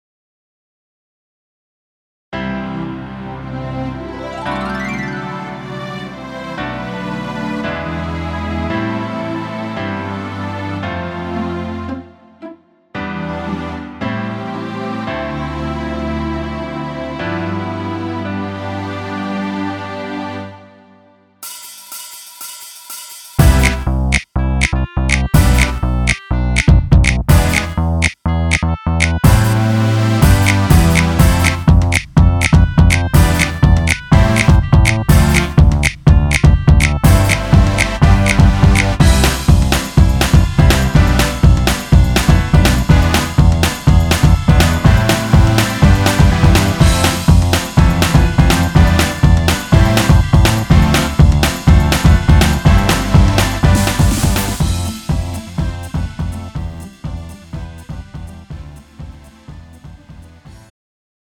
-멜로디MR 가수
음정 남자키 장르 가요